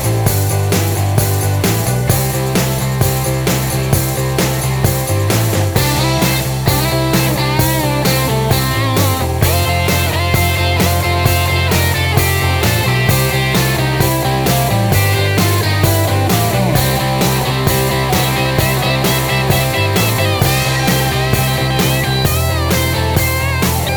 Backing Vocals Rock 3:11 Buy £1.50